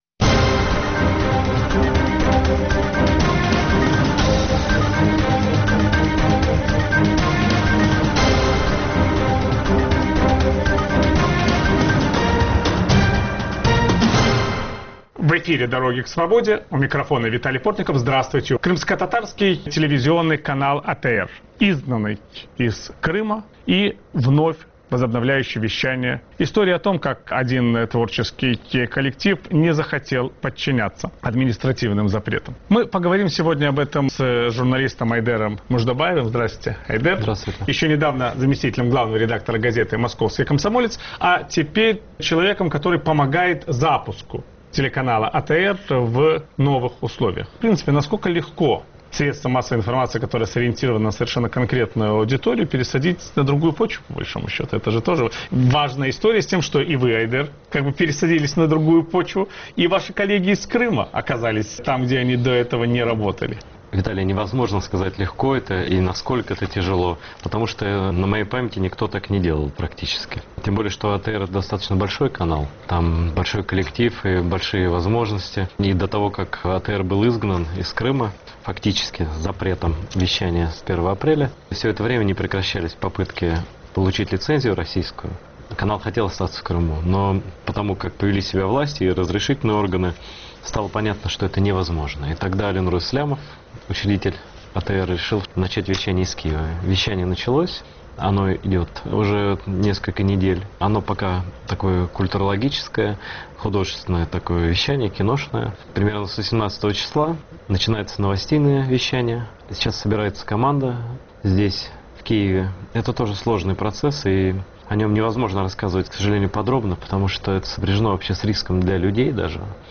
Крымскотатарский канал ATR, изгнанный из Крыма, возвращается к своей аудитории, начав вещание из Киева. О будущем телеканала ведущий программы "Дороги к свободе" Виталий Портников беседует с известным российским журналистом Айдером Муждабаевым.